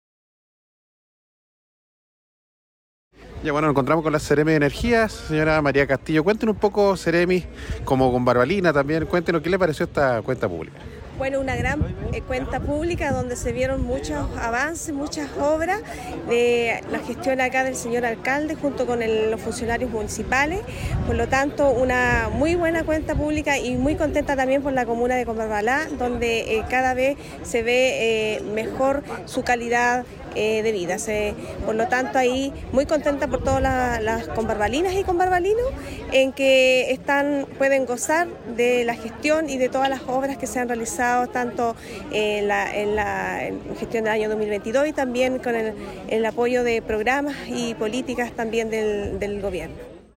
Por su parte la Seremi de Energía, María Castillo, señaló:
SEREMI-de-Energia-Maria-Castillo.mp3